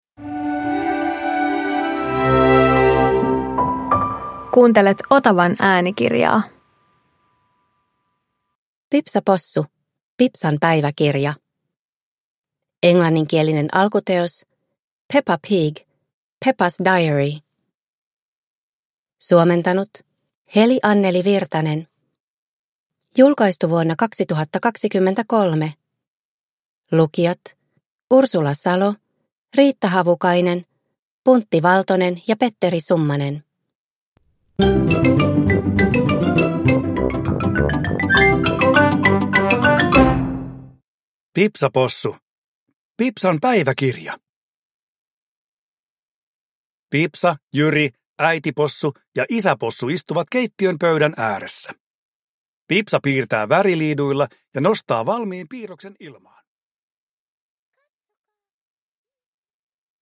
Pipsa Possu - Pipsan päiväkirja – Ljudbok